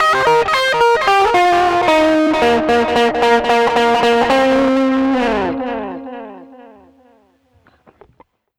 Track 15 - Guitar 07.wav